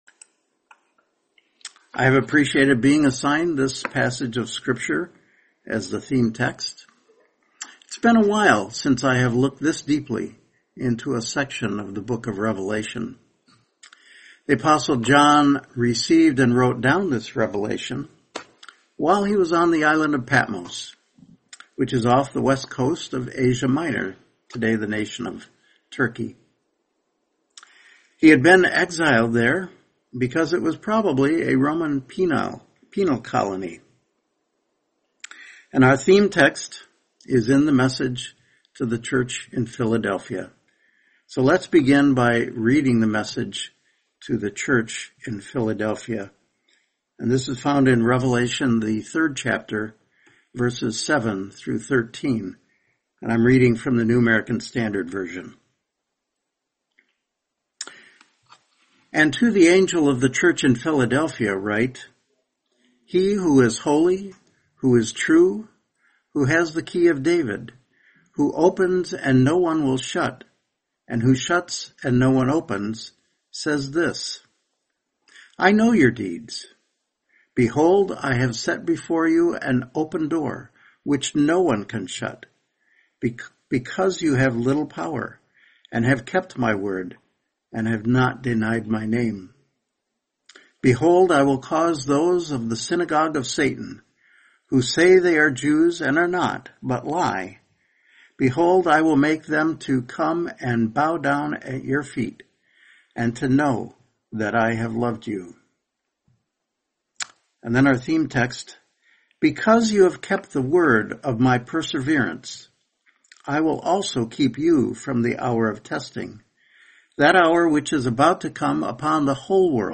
Series: 2026 Highland Park Convention